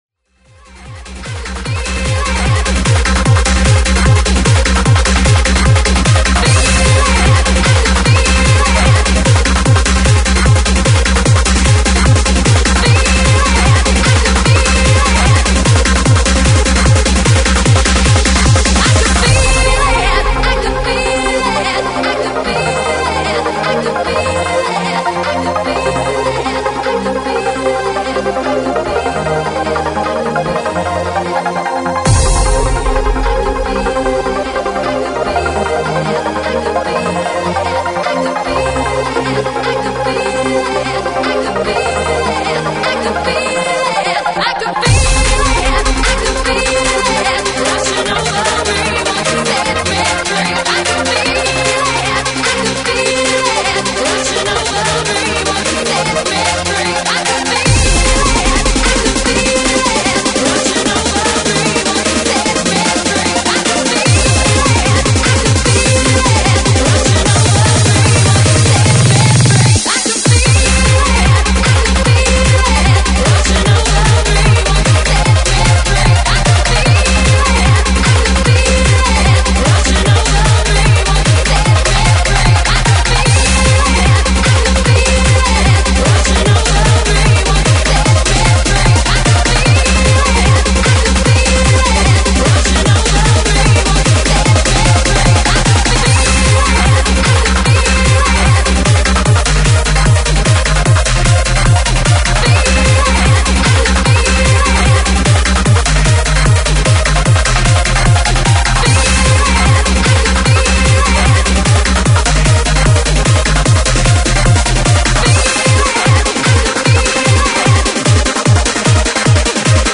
Hard Dance